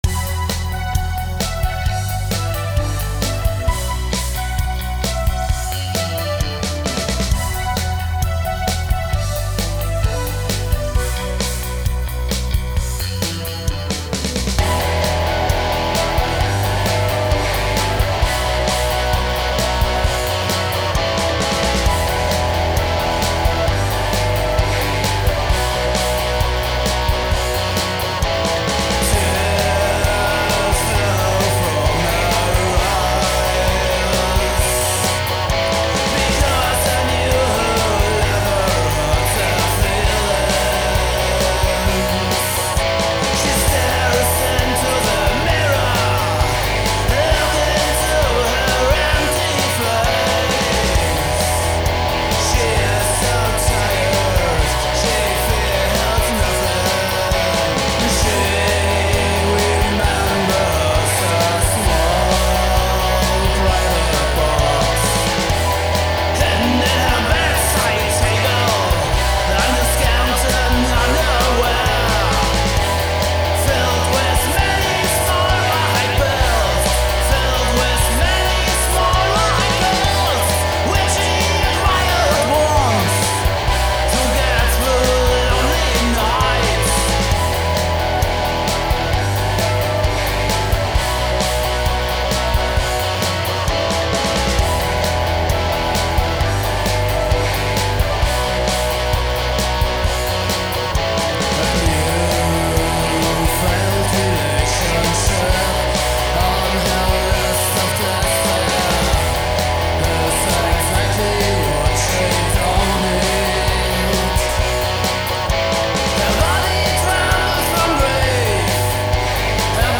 DARKWAVE GUITAR ROCK